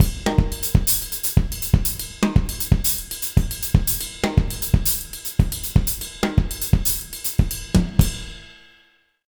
120SALSA04-R.wav